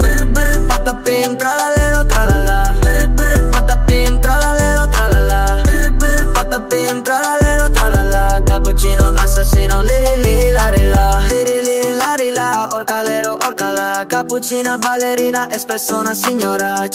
brainrot rap song Meme Sound Effect
brainrot rap song.mp3